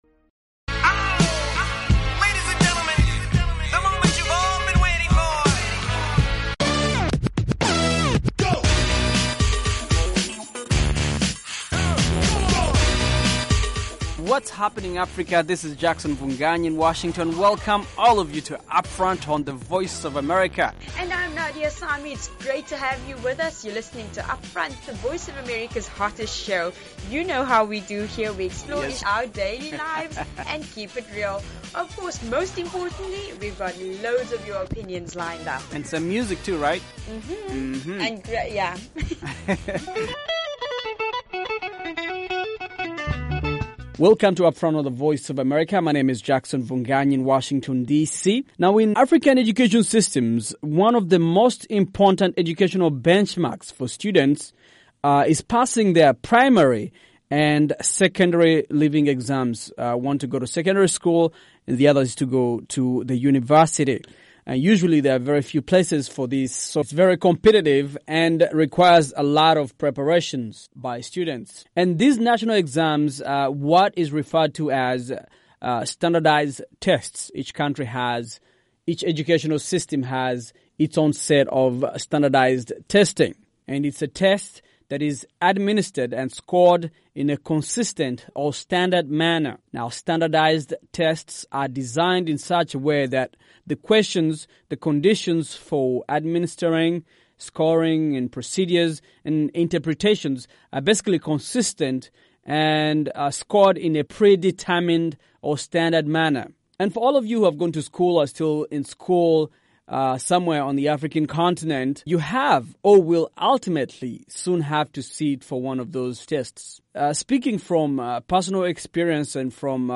On this fresh, fast-paced show, co-hosts